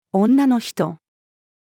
女の人-female.mp3